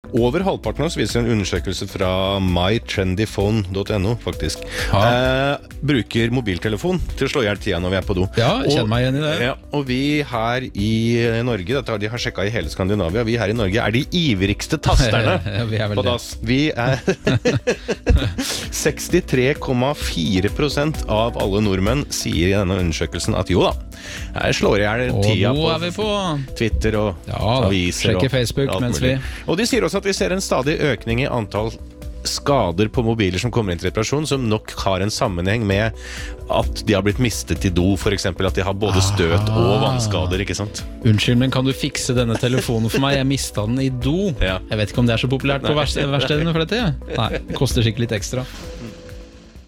Det er faktisk ganske mange telefoner som havner der i løpet av et år, og en del av disse dukker opp til reparasjon hos MyTrendyPhone. La oss begynne med et kort lydklipp fra radiokanalen P4s, som tar for seg akkurat dette fenomenet: